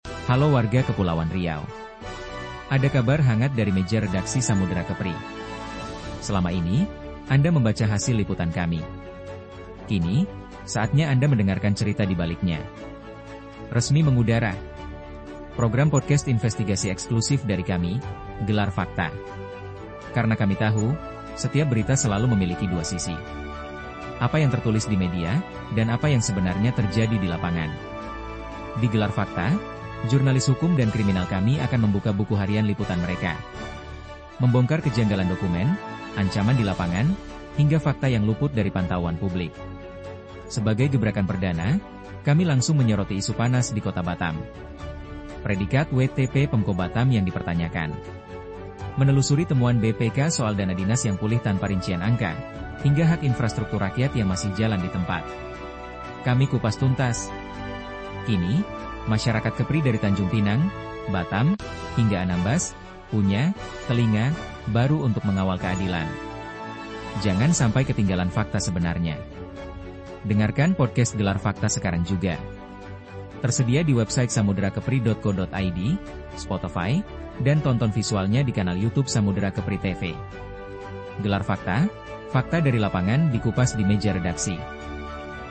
Program ini adalah ruang buka-bukaan dapur redaksi, di mana para jurnalis desk hukum dan kriminal SAMUDERA KEPRI akan menceritakan temuan investigasi, kejanggalan dokumen, hingga fakta-fakta lapangan yang sering kali luput dari pantauan publik.